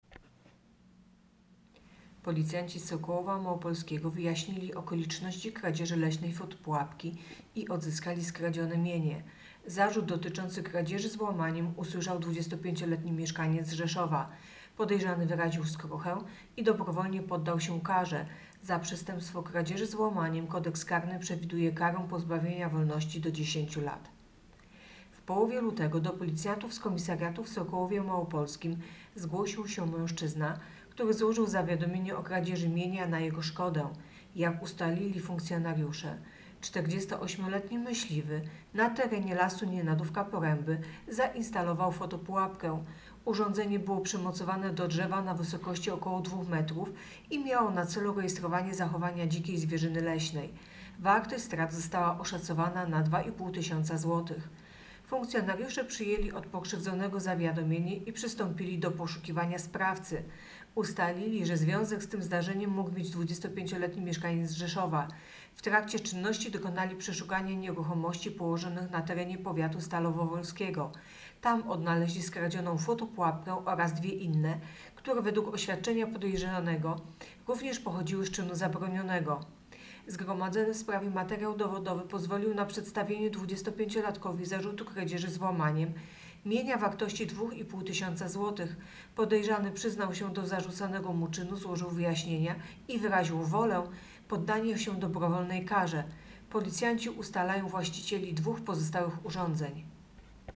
Opis nagrania: Nagranie informacji pt. Policjanci odzyskali skradzioną fotopułapkę.